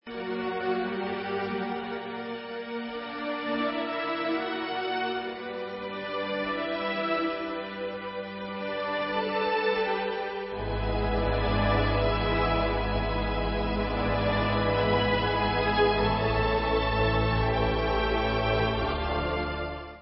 1996 studio album w.
Pop/Symphonic